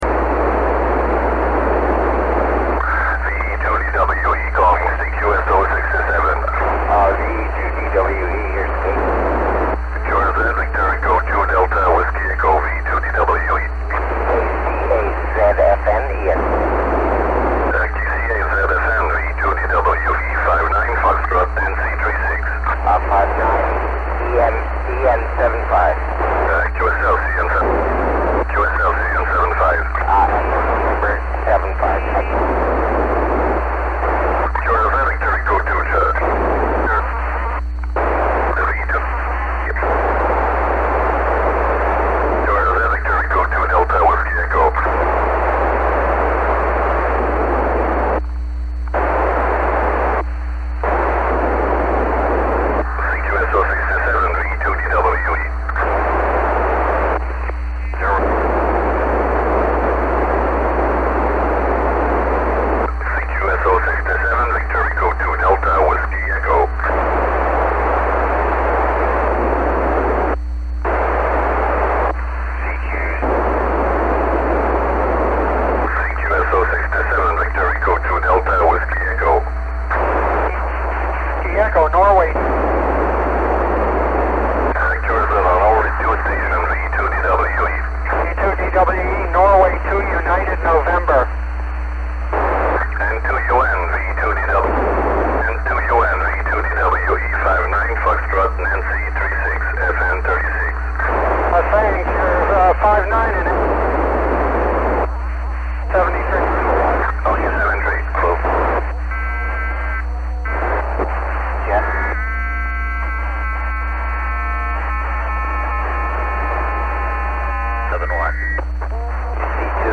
This was a 17 degree western pass for me.